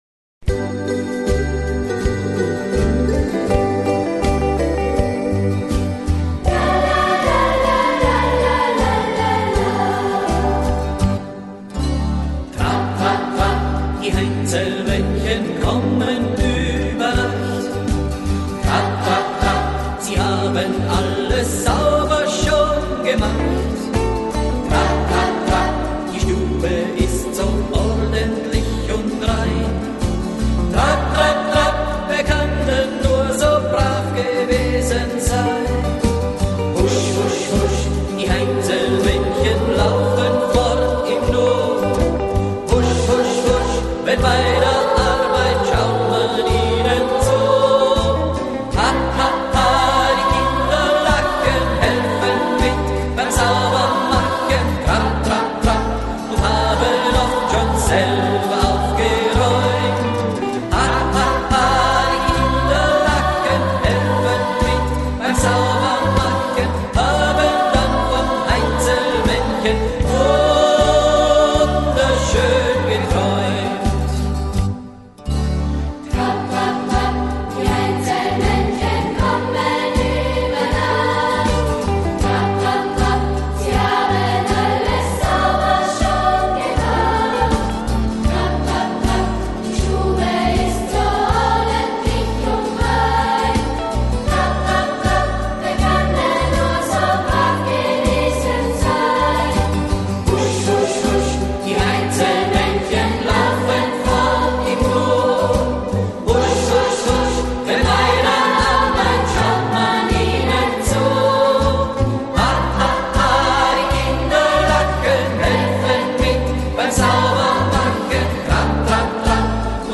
Platzkonzerte